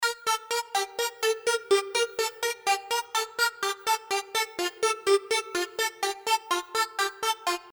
Как по мне так слышаться pw модуляция...